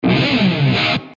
ロックな効果音をご自由にダウンロードして下さい。
Distortion Sound Guitar
Distortionブオーン…ジャ(E♭)01 22.94 KB